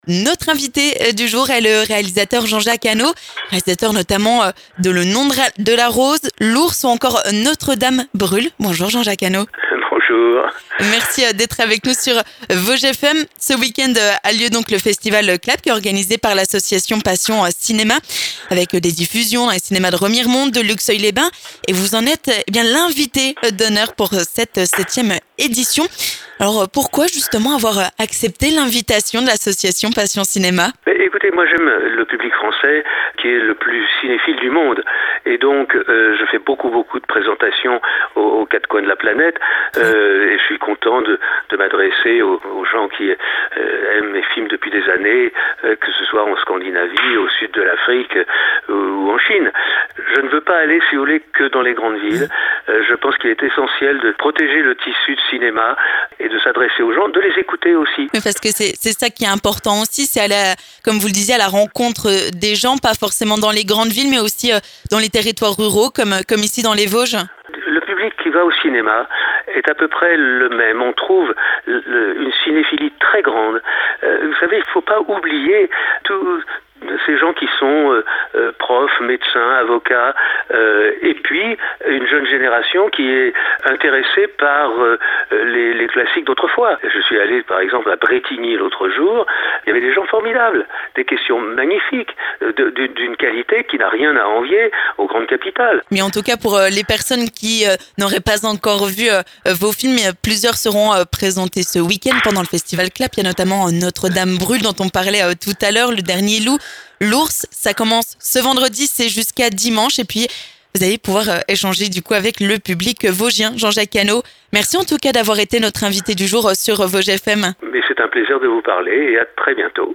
Jean-Jacques Annaud est notre invité du jour sur Vosges FM !